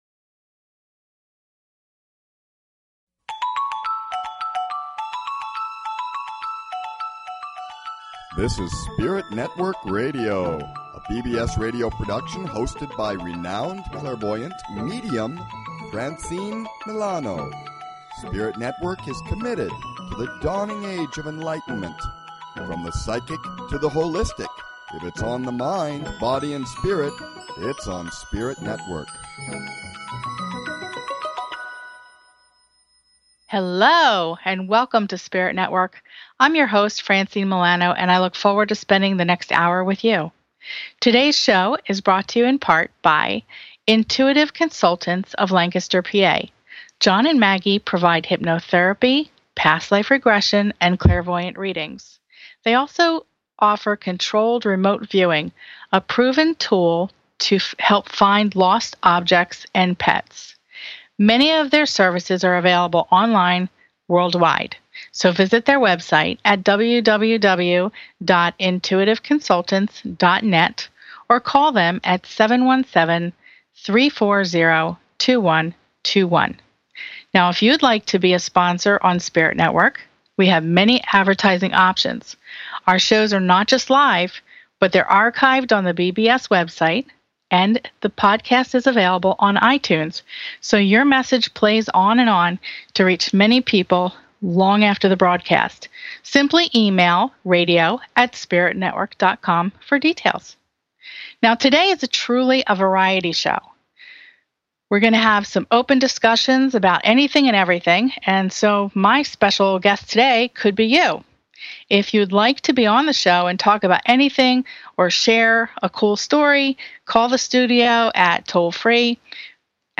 In the episode, listeners are the guests! Listeners call in and email about all kinds of spiritual and metaphysical topics including dreams, past lives, our loved ones on the other side and Reiki healing.